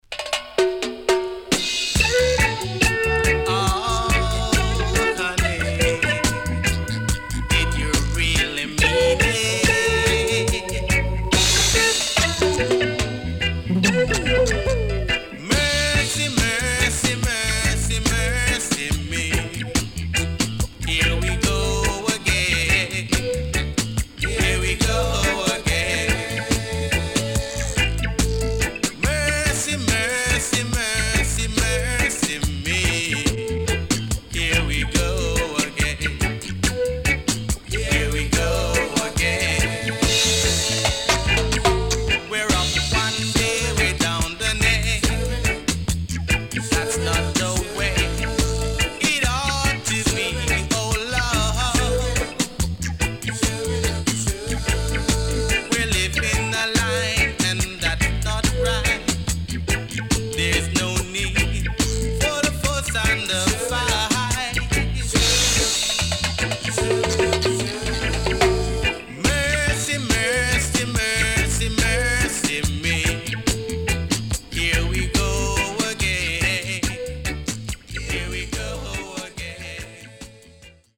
W-Side Good Vocal.Good Condition
SIDE A:少しノイズ入りますが良好です。